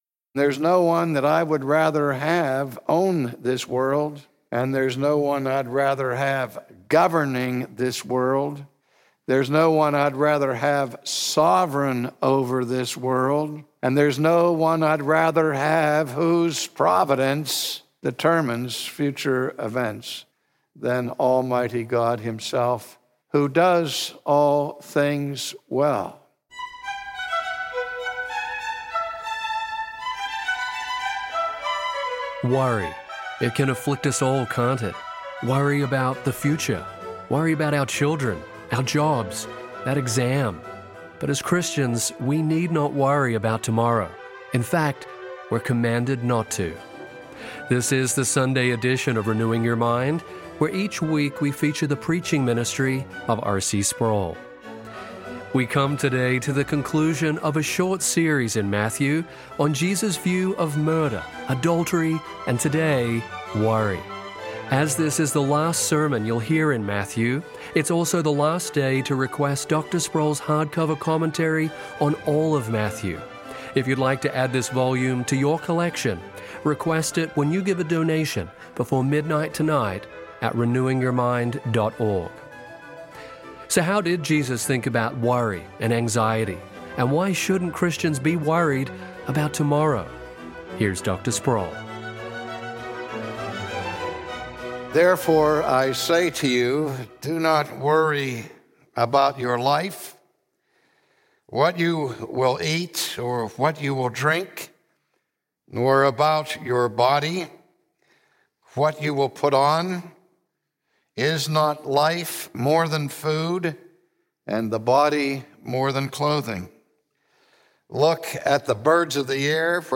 In fact, Jesus commands against it. From his sermon series in the gospel of Matthew